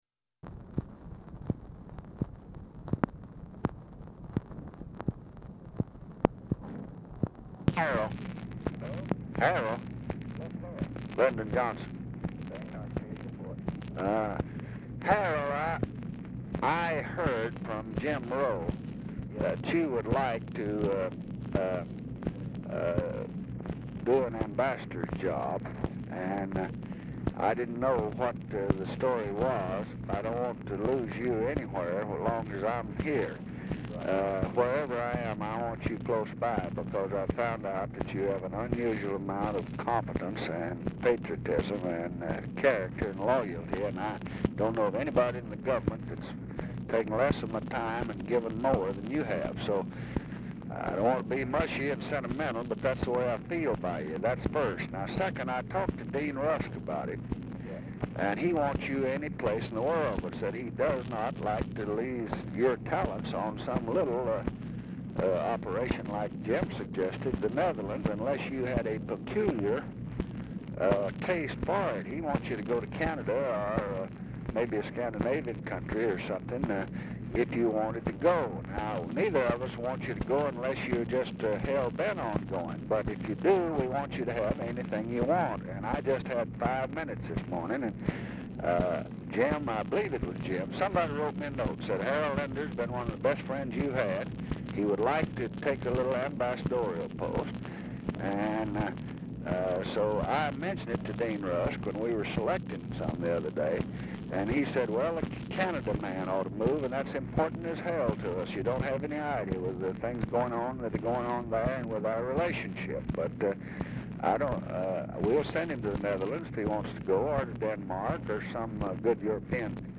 LINDER IS ALMOST INAUDIBLE
Format Dictation belt
Specific Item Type Telephone conversation